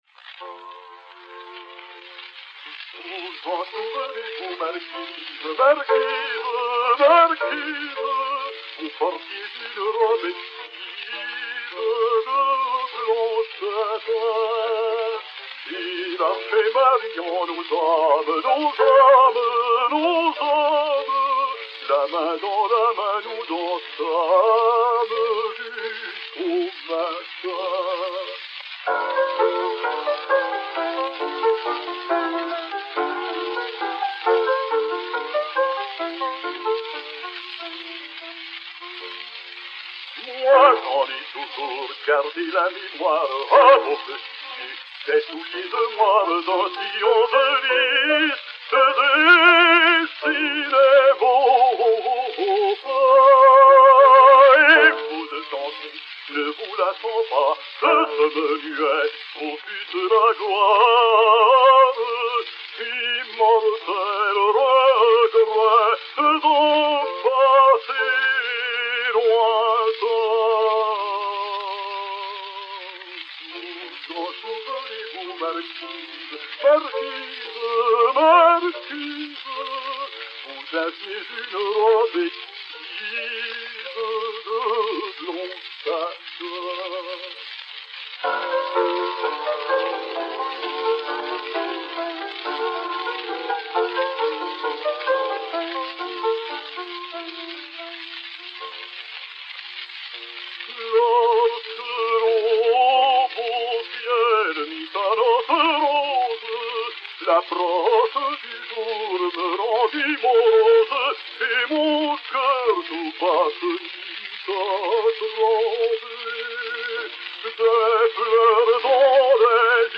Mélodie, poésie d'Armand SILVESTRE, musique de Jules MASSENET (1888).
Victor Maurel, baryton, avec accompagnement de piano
Disque Pour Gramophone 2-32810, mat. 1628 F1, enr. à Paris en 1903